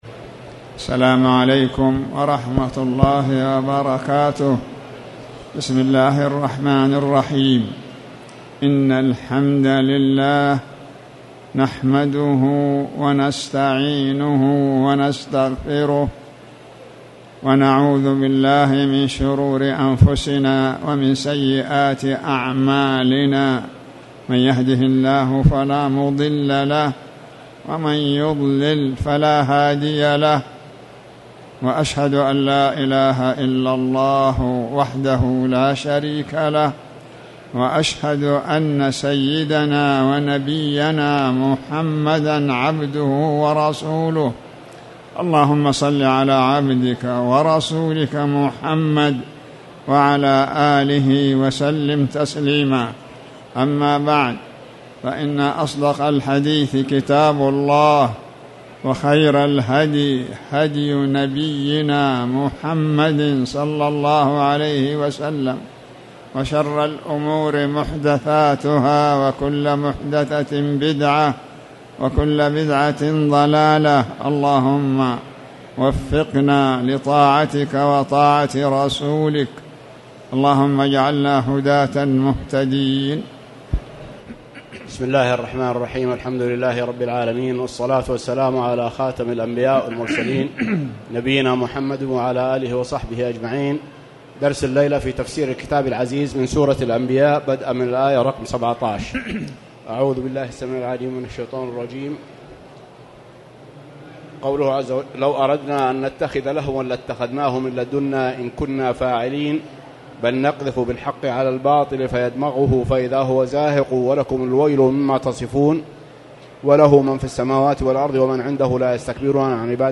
تاريخ النشر ٢٥ شوال ١٤٣٨ هـ المكان: المسجد الحرام الشيخ